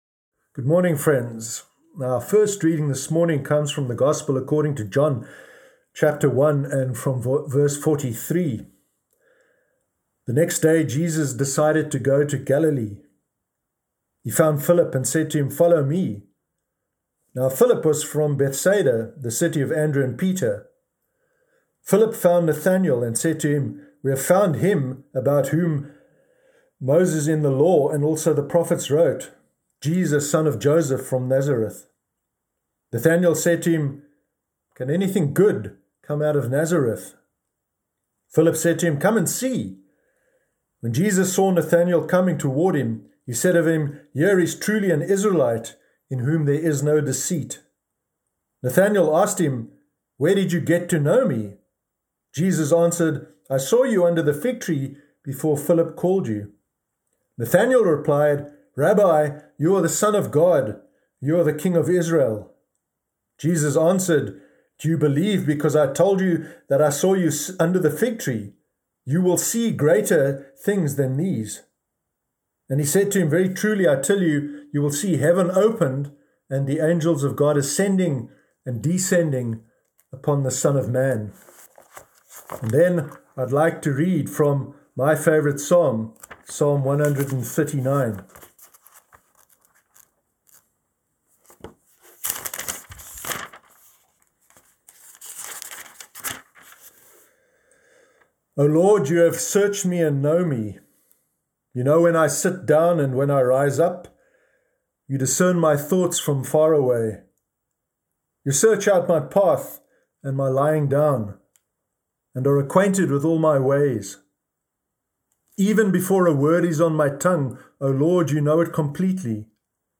Sermon Sunday 17 January 2021